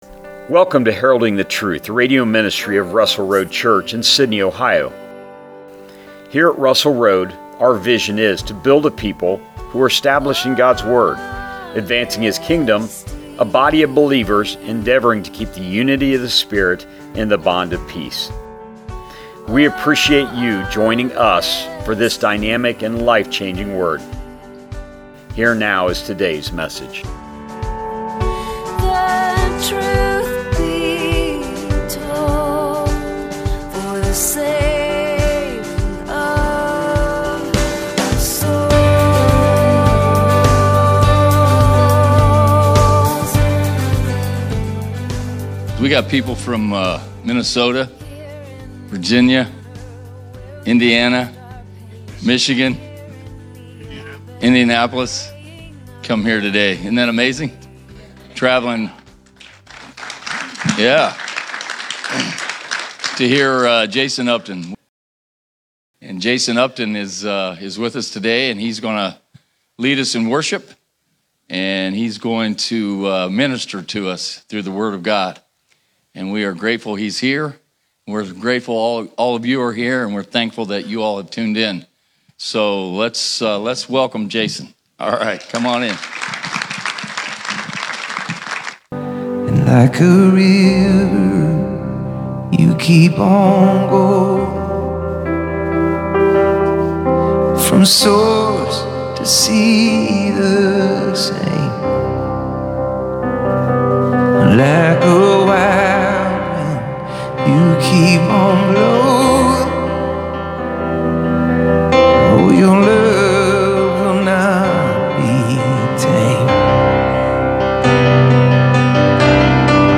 Sermons | Russell Road Church